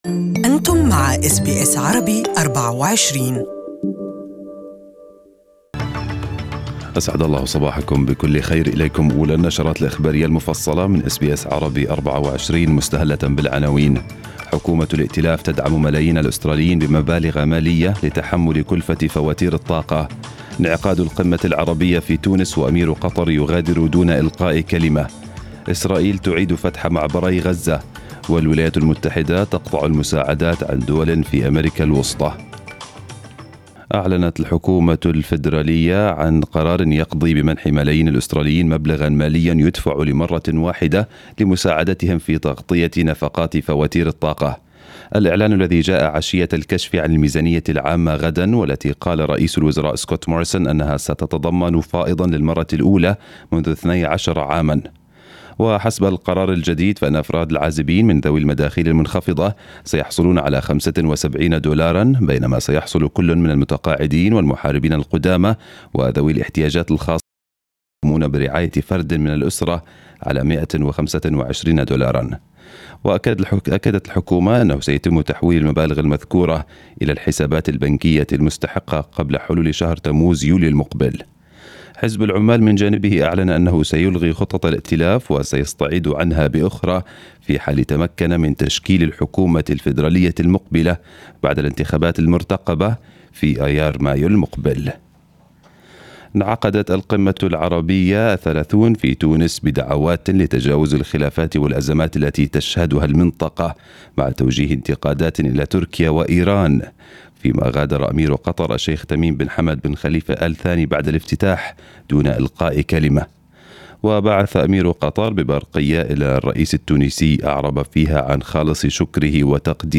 News bulletin of the day in Arabic